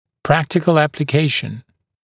[‘præktɪkl ˌæplɪ’keɪʃn][‘прэктикл ˌэпли’кейшн]практическое применение